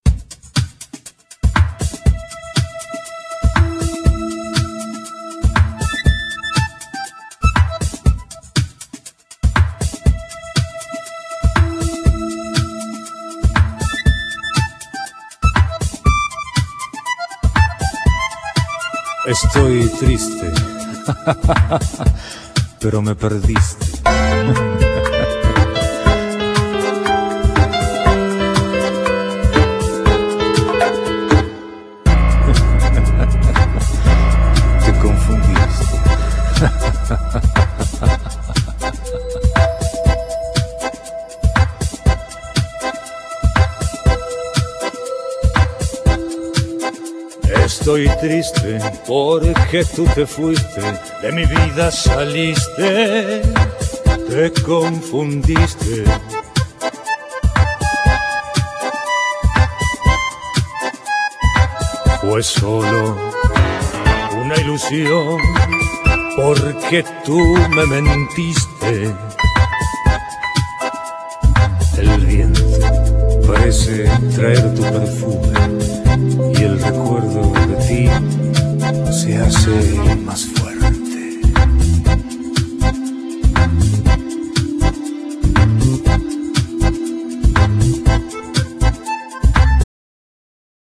Genere : Pop latino